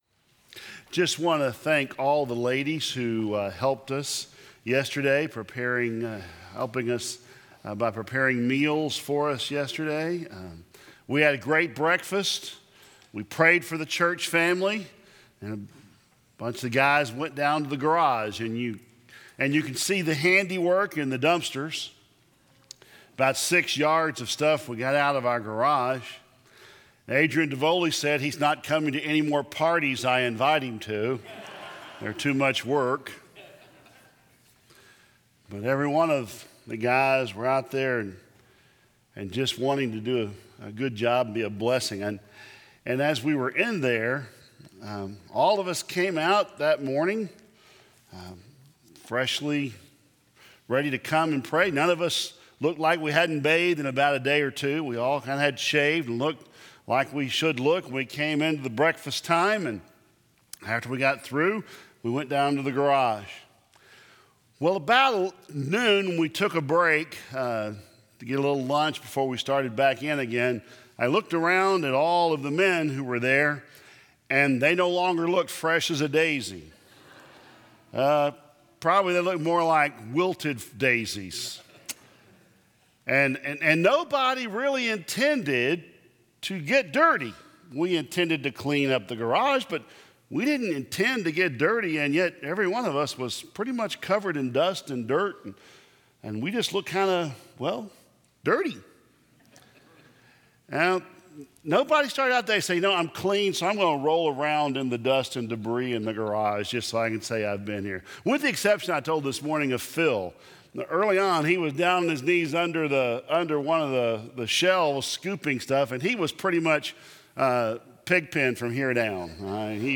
2017 Related Share this sermon